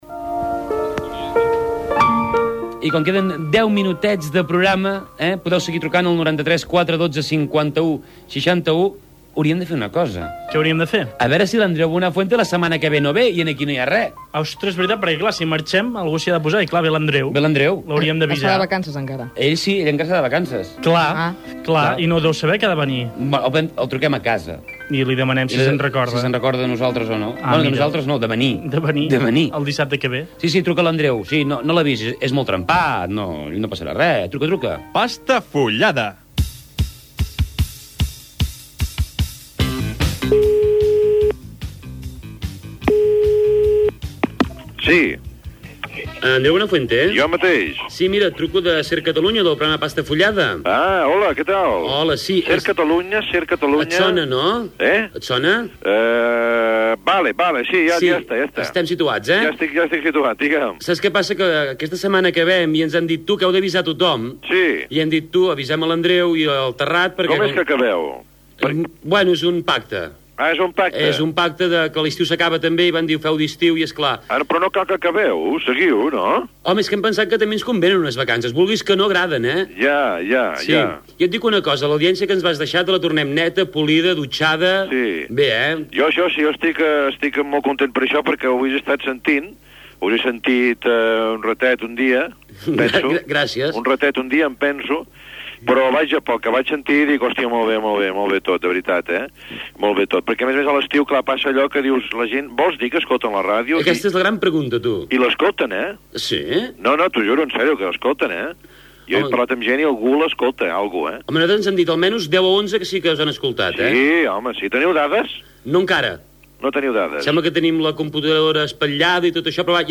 Entrevista a Andreu Buenafuente
Entreteniment